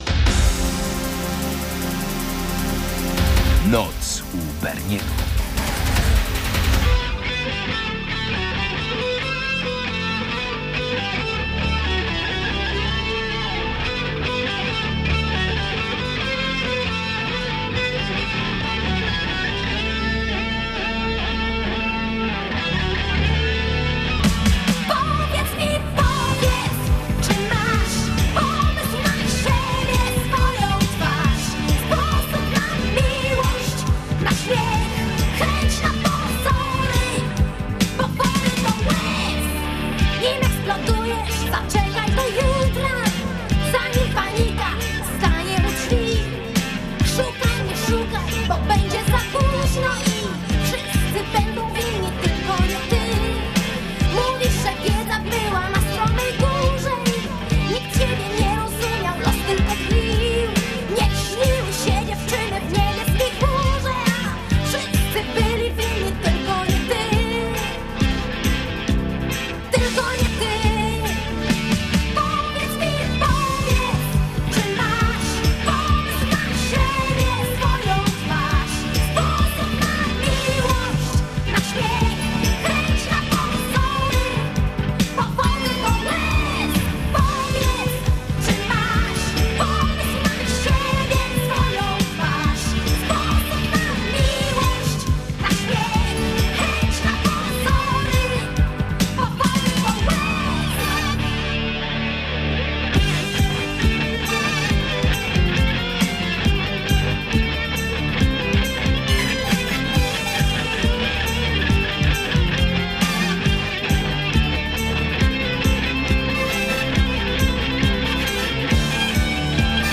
Dominujący gatunek: polskie piosenki